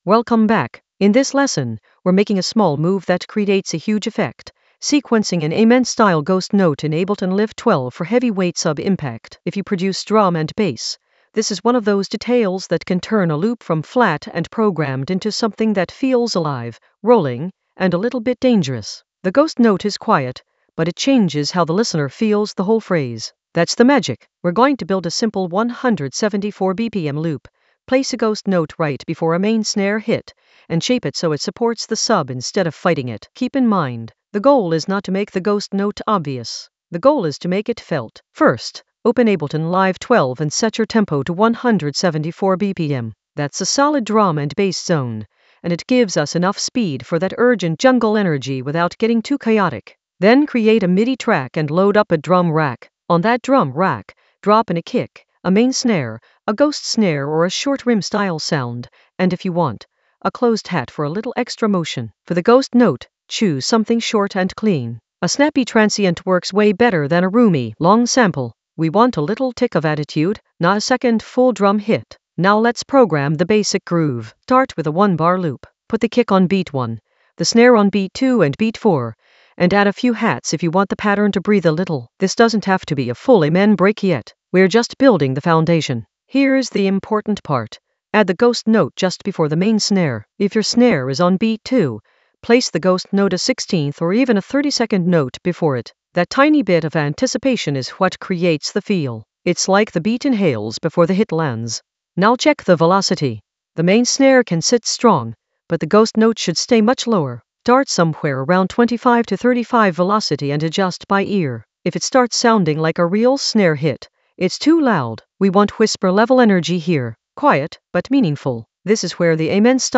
An AI-generated beginner Ableton lesson focused on Sequence an Amen-style ghost note for heavyweight sub impact in Ableton Live 12 in the Sound Design area of drum and bass production.
Narrated lesson audio
The voice track includes the tutorial plus extra teacher commentary.